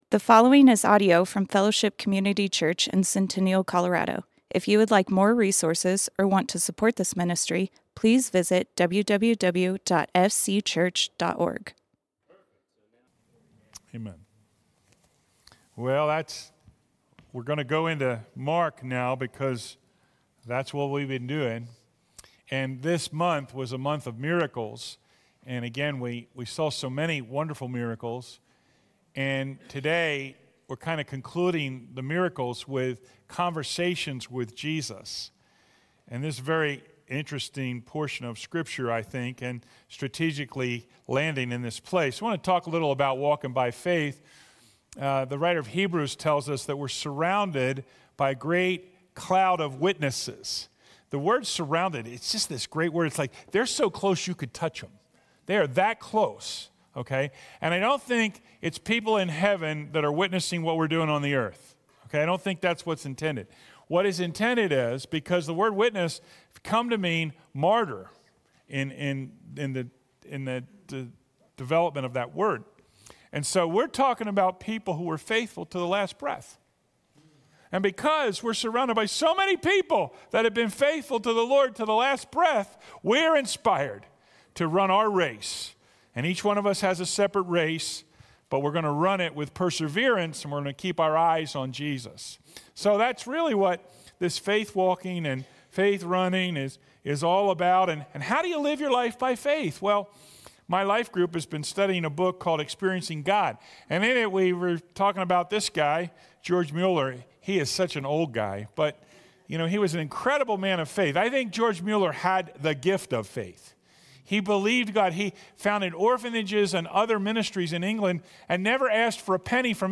Fellowship Community Church - Sermons Conversations with Jesus Play Episode Pause Episode Mute/Unmute Episode Rewind 10 Seconds 1x Fast Forward 30 seconds 00:00 / Subscribe Share RSS Feed Share Link Embed